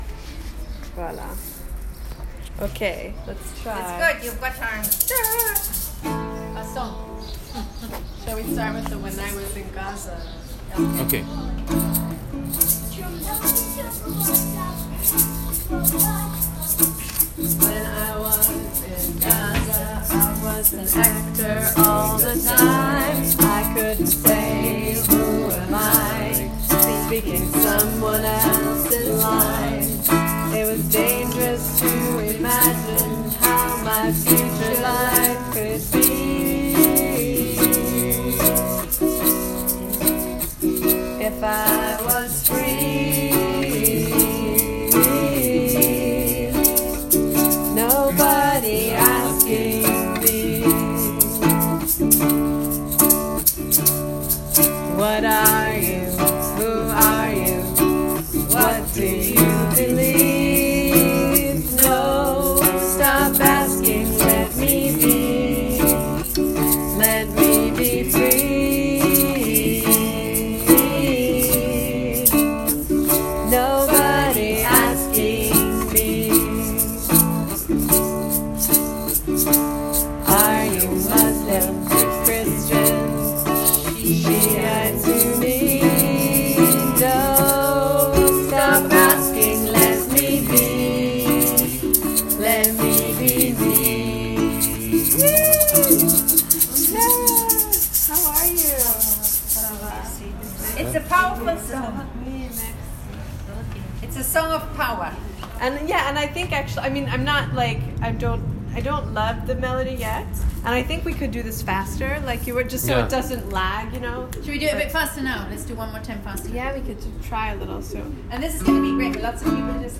This is a song that is not yet complete.
This is a recording from the day we began working on this song: